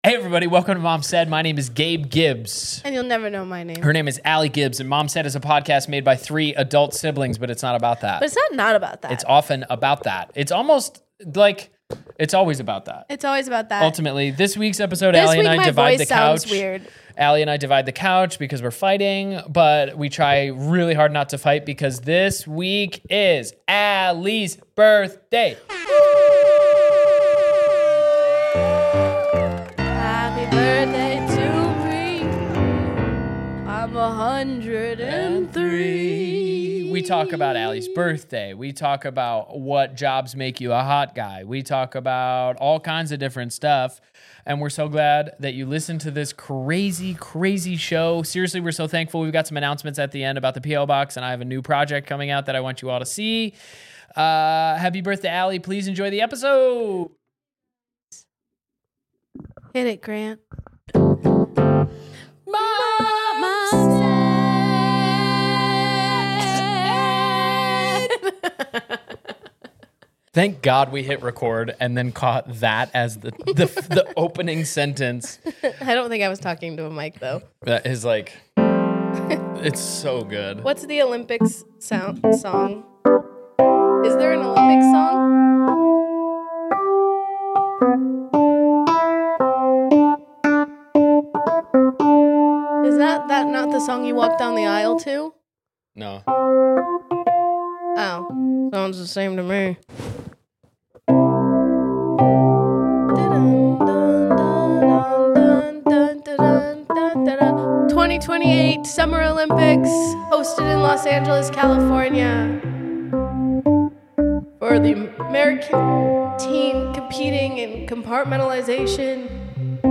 This week we announce the definitive top 5 hottest jobs a man can have … and they may surprise you. So tune in as the siblings discuss career advice, cockney accents, and someone’s birthday!